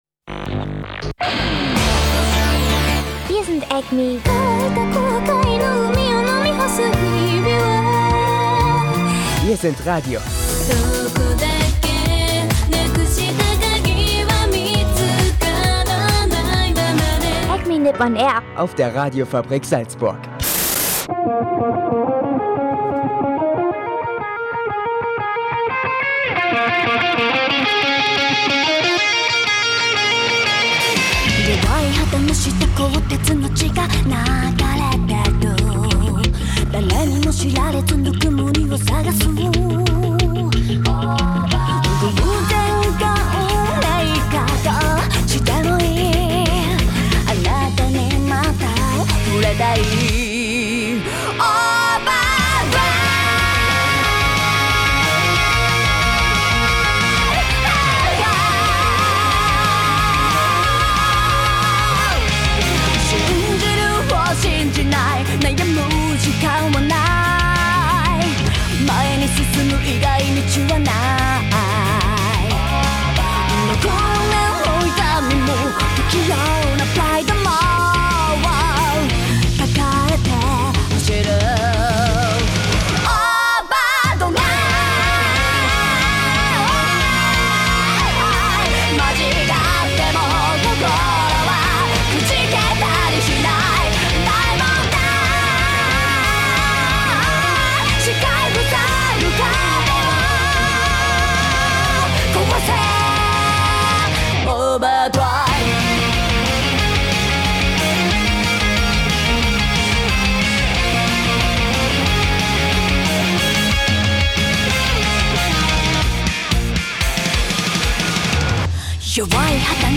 Diese Woche noch einmal eine frische Japan-Musik- und Info-Show aus dem Studio der Radiofabrik, mit Anime-News, Japan-News und Ankündigung für die Wahl für Acme.Platin 2016.